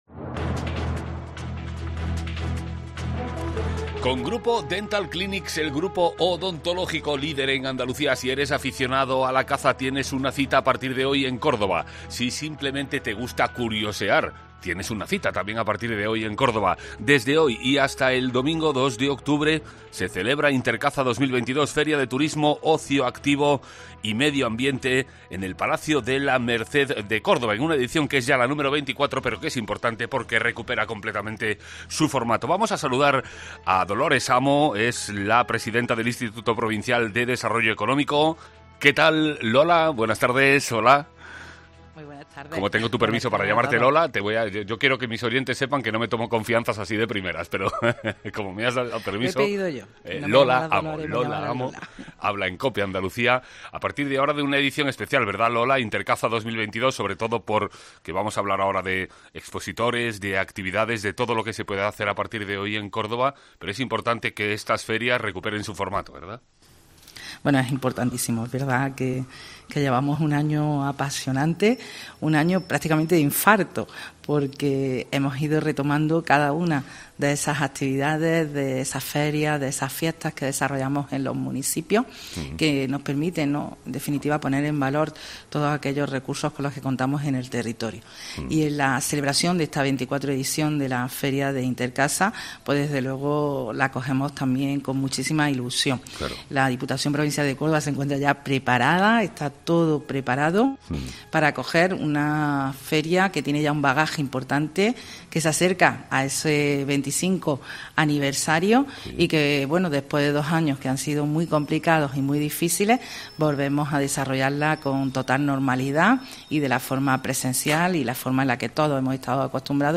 Intercaza 2022, Feria de Turismo, Ocio Activo y Medio Ambiente, vuelve del 29 de septiembre al 2 de octubre al Palacio de la Merced en una edición, la número 24, que recupera el formato anterior a la pandemia y que contará con más de 150 actividades que se desarrollarán tanto en la zona exterior como en la carpa y todos los espacios de la Diputación de Córdoba. Hoy hemos hablado con Lola Amo, presidenta del Institupo Provincial de Desarrollo de Córdoba.